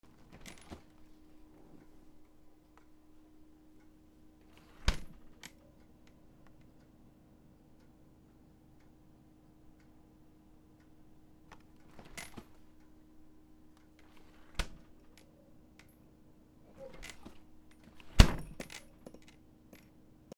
冷蔵庫 開け閉め